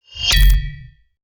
UI_SFX_Pack_61_31.wav